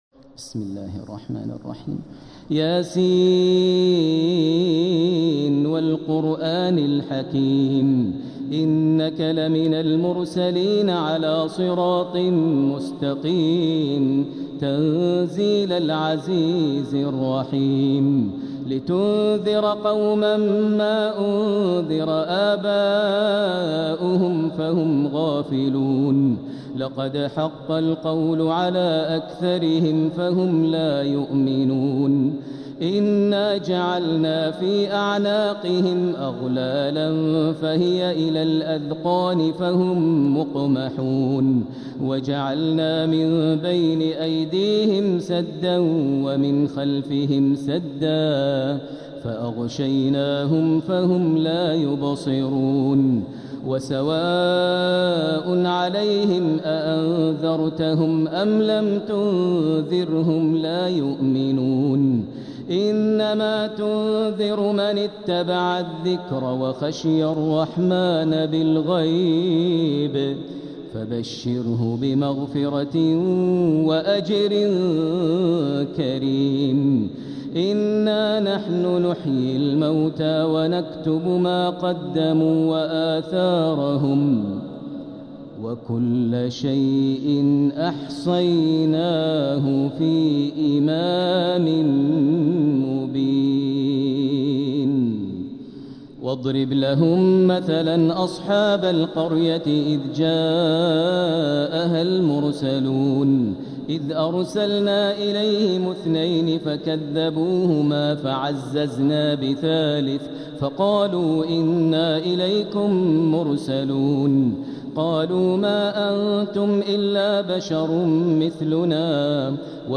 سورة يس | مصحف تراويح الحرم المكي عام 1446هـ > مصحف تراويح الحرم المكي عام 1446هـ > المصحف - تلاوات الحرمين